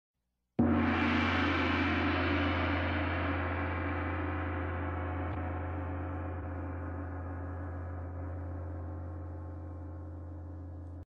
Gong